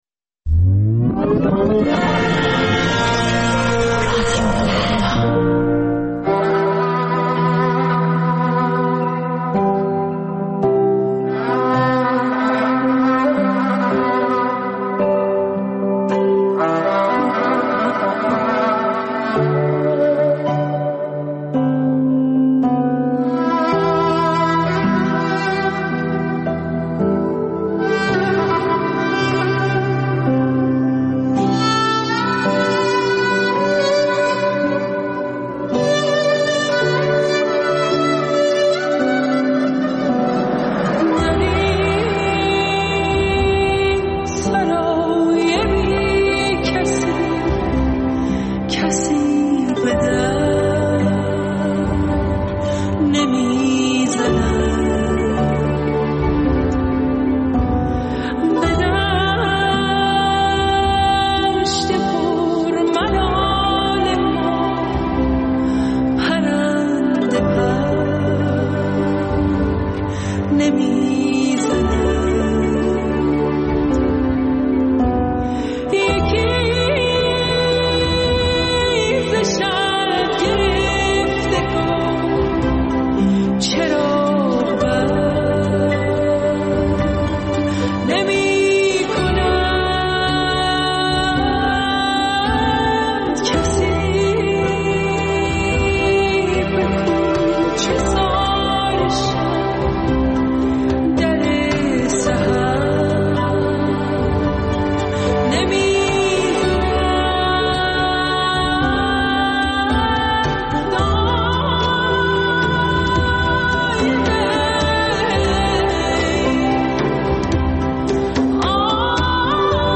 دقایقی با موسیقی جز سول و بلوز